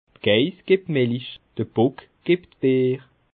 Expressions populaires
Bas Rhin
Ville Prononciation 67
Schiltigheim